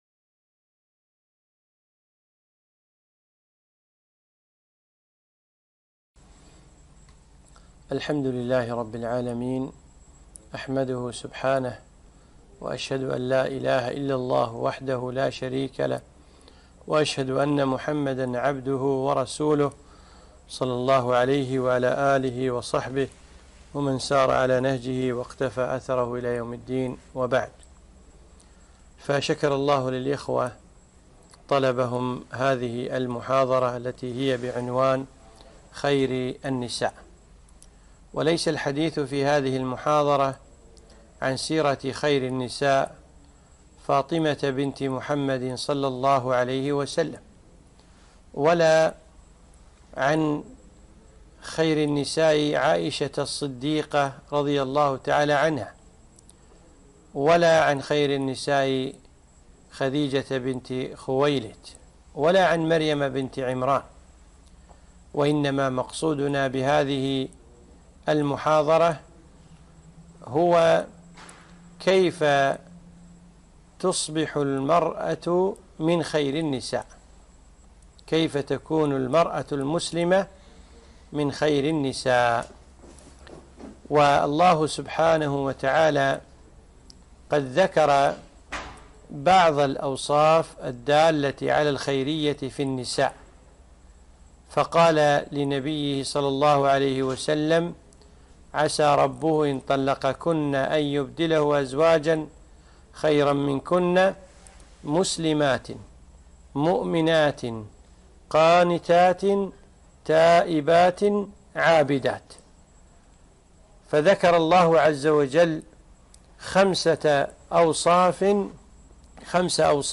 محاضرة نافعة - خيـر النـسـاء